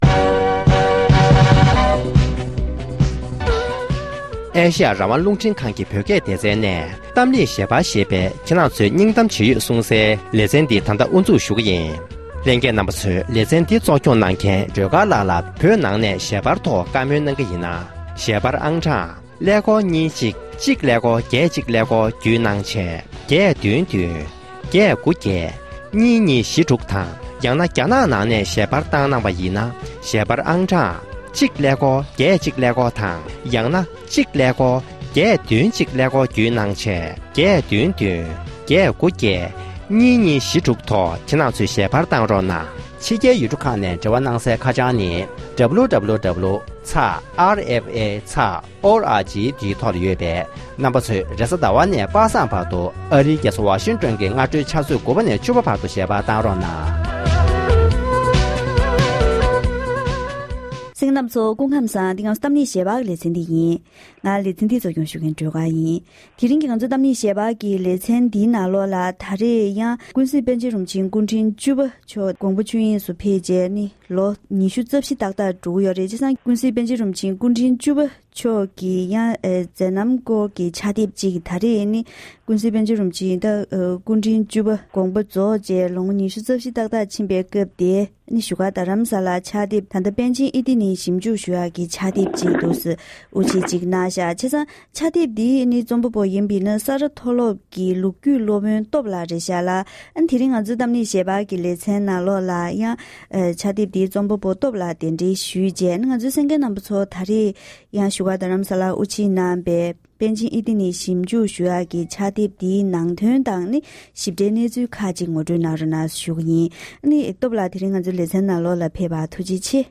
གཏམ་གླེང་ཞལ་པར་ལེ་ཚན་